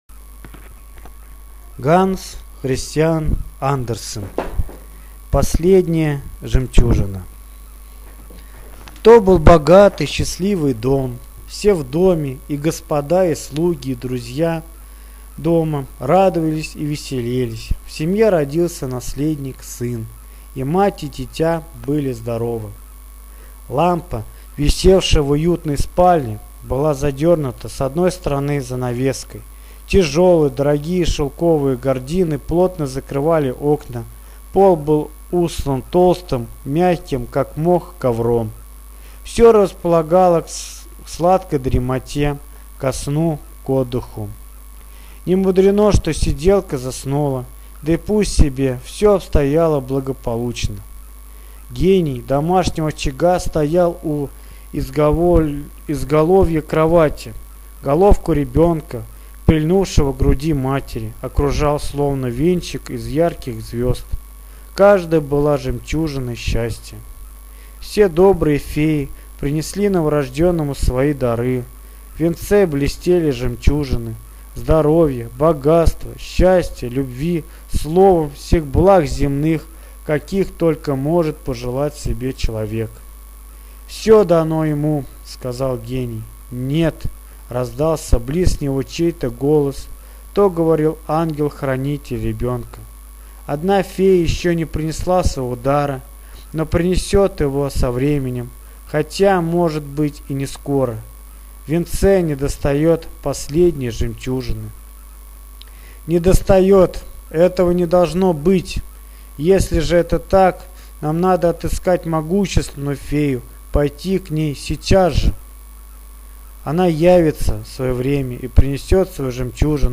Аудиосказка Последняя жемчужина - слушать сказку Андерсена онлайн бесплатно